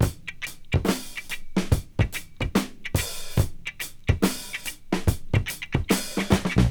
• 72 Bpm Breakbeat Sample G Key.wav
Free drum beat - kick tuned to the G note. Loudest frequency: 1589Hz
72-bpm-breakbeat-sample-g-key-KQf.wav